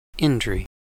The indri (/ˈɪndri/
En-us-indri.ogg.mp3